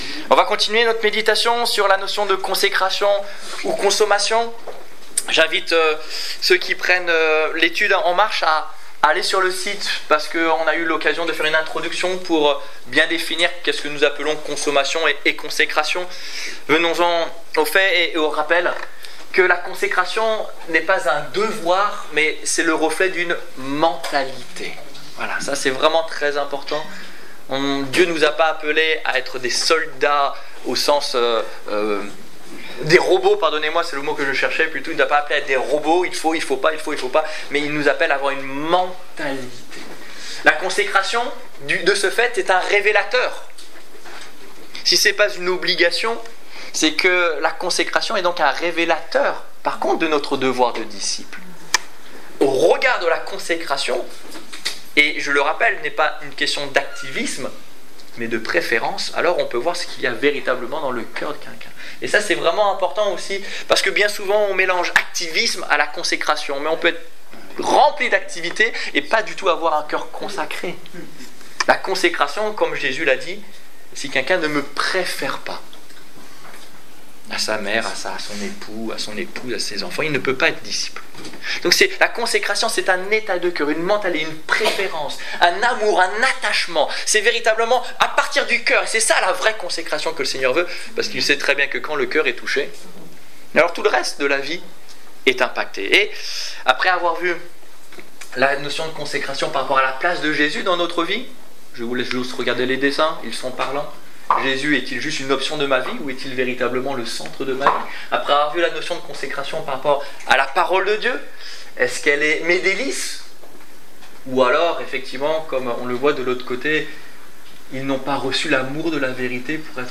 - Le service Détails Prédications - liste complète Culte du 14 juin 2015 Ecoutez l'enregistrement de ce message à l'aide du lecteur